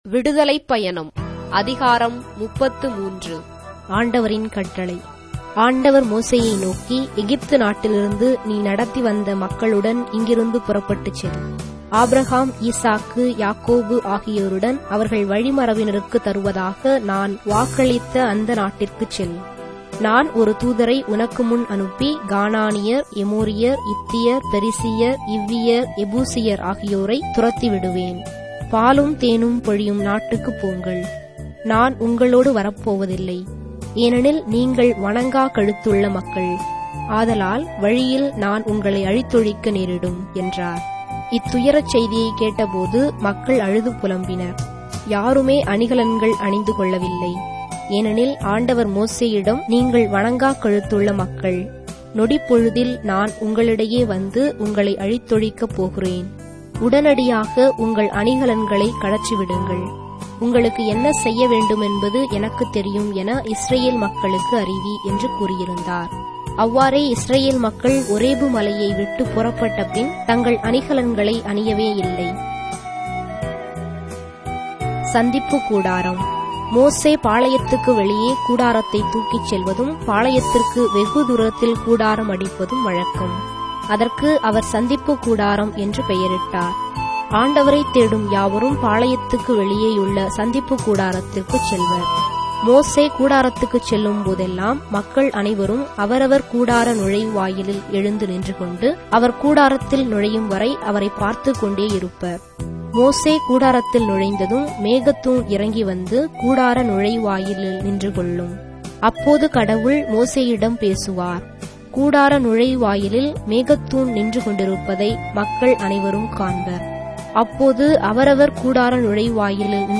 Tamil Audio Bible - Exodus 33 in Ecta bible version